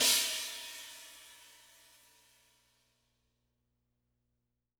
R_B China 01 - Close.wav